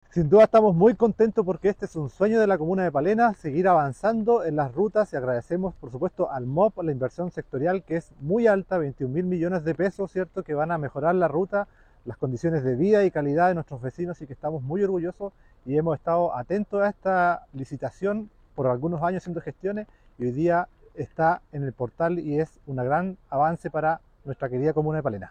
Asimismo el alcalde de Palena, Julio Delgado, destacó el avance de una iniciativa tan anhelada por los vecinos de su comuna.
2-CUNA-ALCALDE-PALENA.mp3